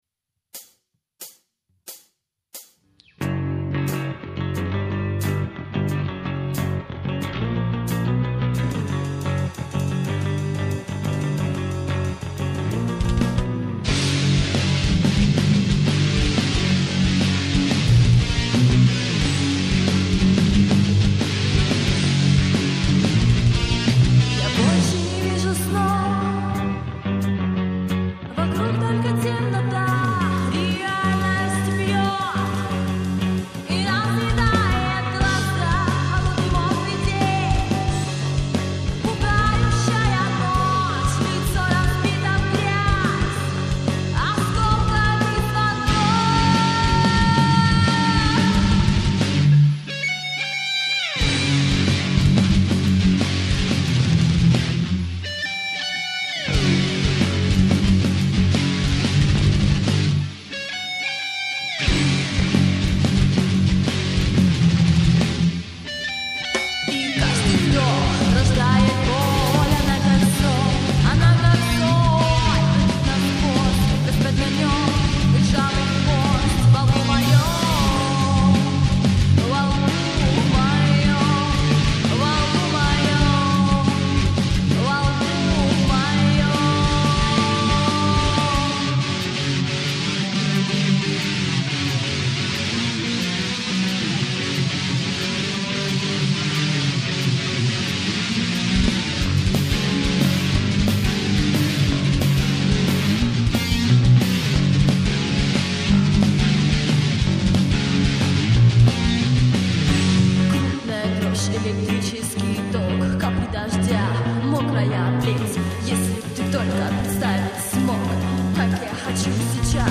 the rock group